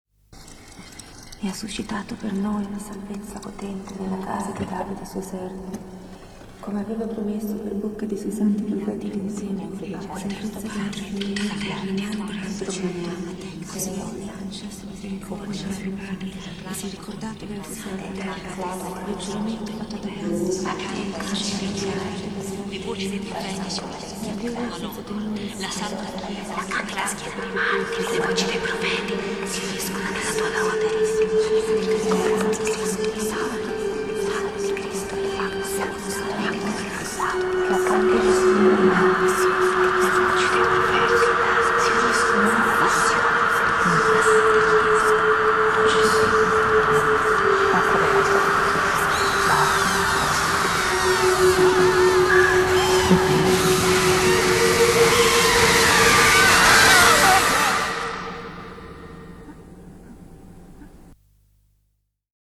The track is eerily haunting.